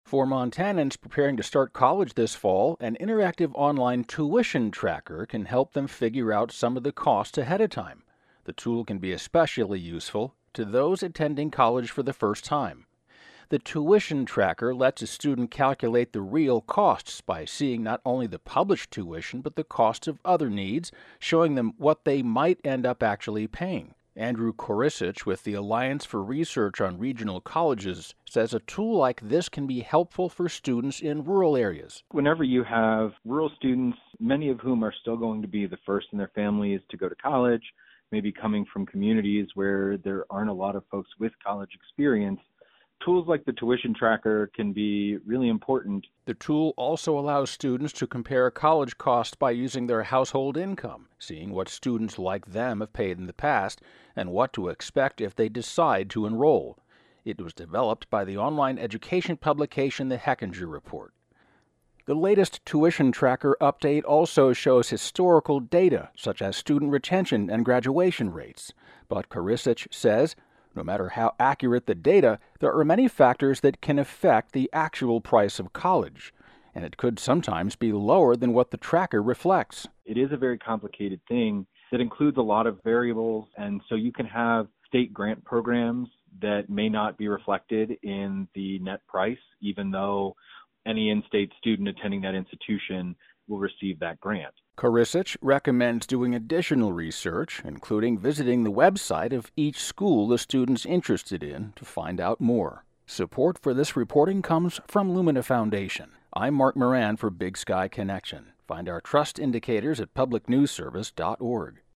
(kor-ISS-ich)